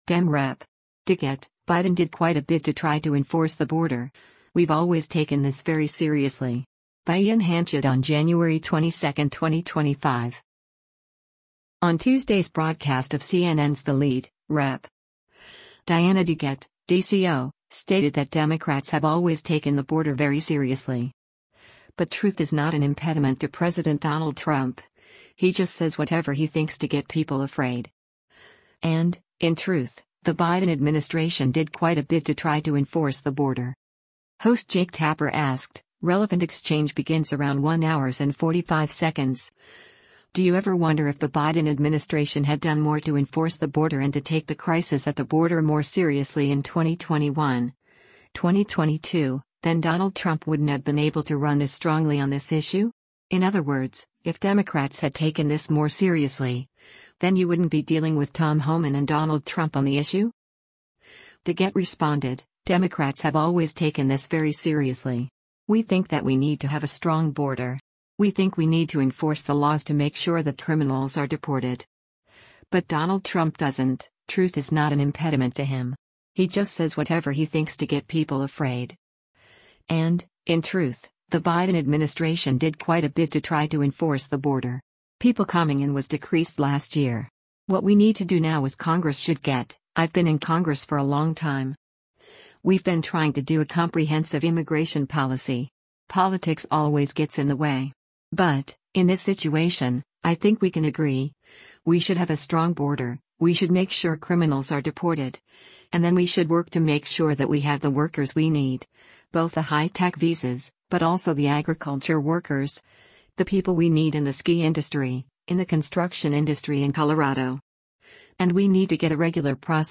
On Tuesday’s broadcast of CNN’s “The Lead,” Rep. Diana DeGette (D-CO) stated that “Democrats have always” taken the border “very seriously.” But “truth is not an impediment” to President Donald Trump, “He just says whatever he thinks to get people afraid. And, in truth, the Biden administration did quite a bit to try to enforce the border.”